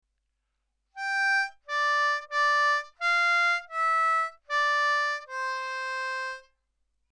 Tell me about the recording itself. Now let’s learn the chunks.